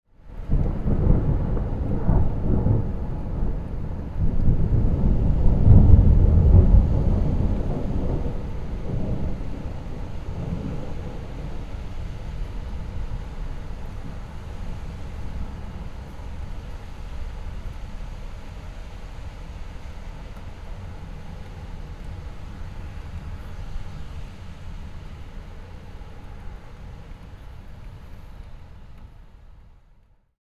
Distant Storm Thunder Sound Effect
Description: Distant storm thunder sound effect. Deep thunder rumble sound effect with rain and wind.
Heavy thunder, storm sounds, rain sounds, wind sounds, atmospheric sound effect.
Distant-storm-thunder-sound-effect.mp3